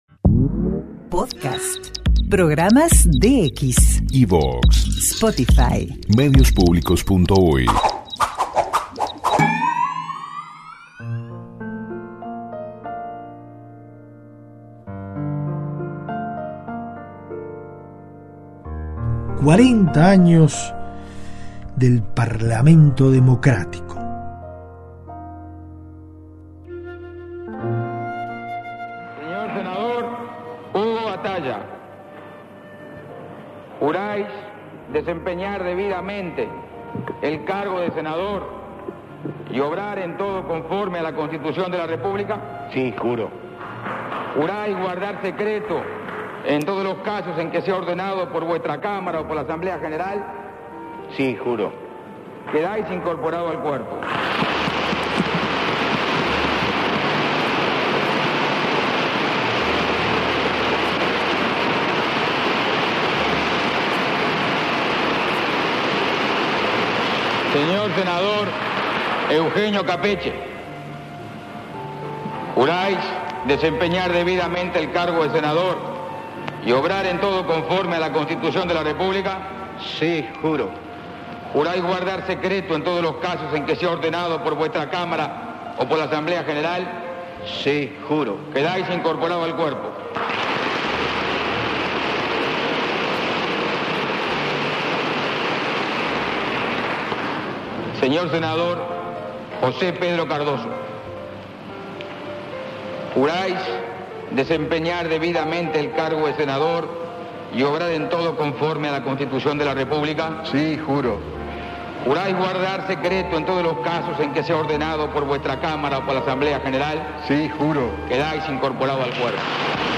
40 años de la asunción del Parlamento democrático en la voz de sus protagonistas, José Germán Araújo en un diario 30 del 15 de febrero de 1984.